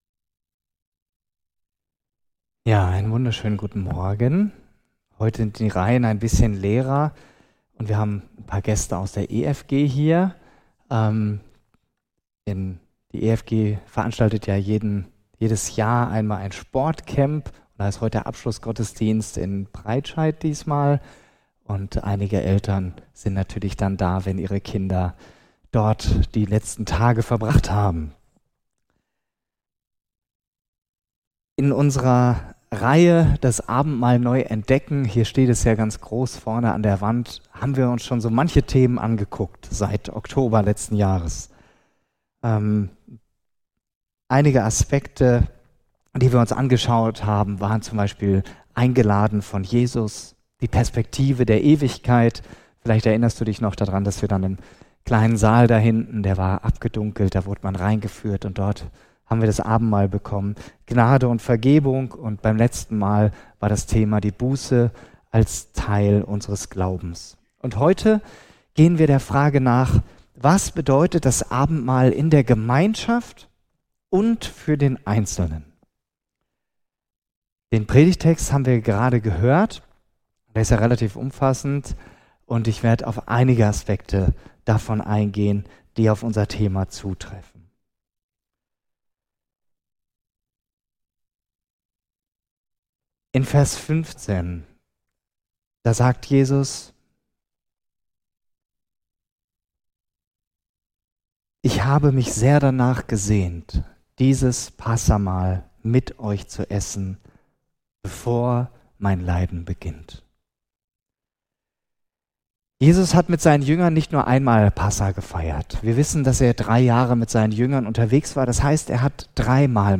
Predigen und andere Vorträge